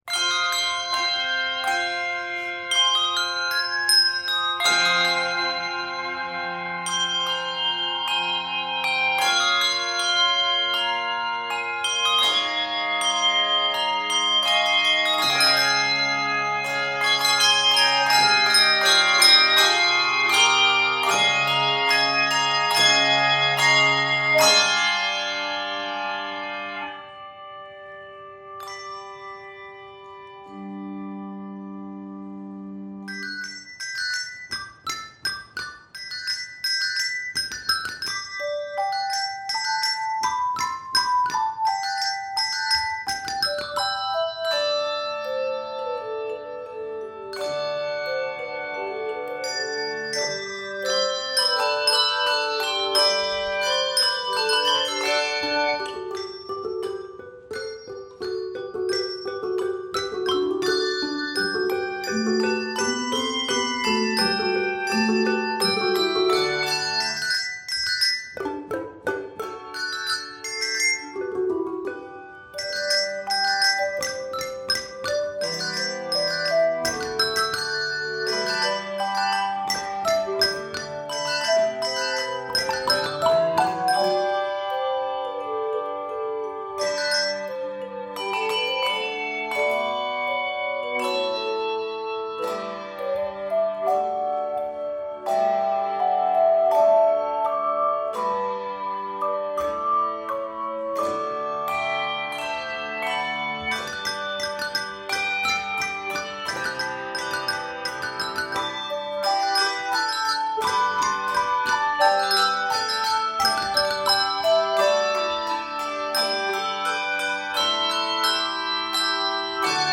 For handbells and chimes, level 4